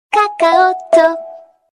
Kakaotalk Sound Effect Free Download